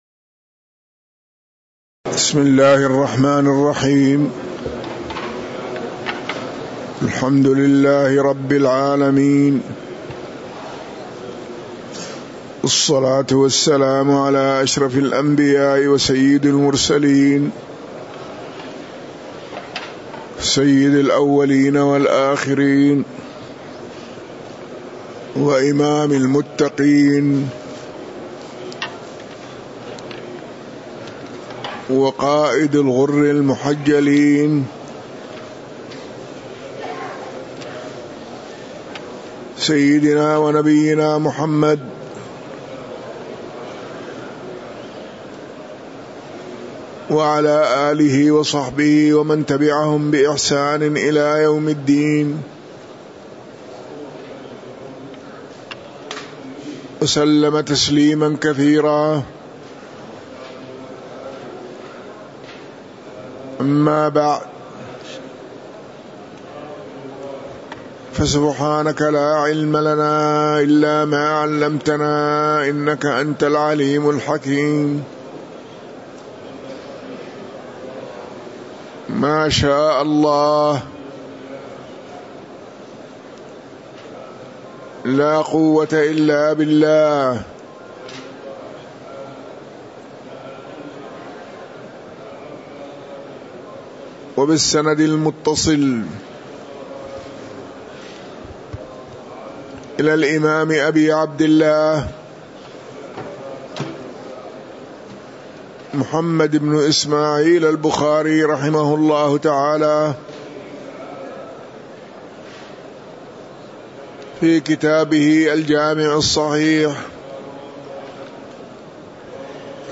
تاريخ النشر ٢١ ذو الحجة ١٤٤٣ هـ المكان: المسجد النبوي الشيخ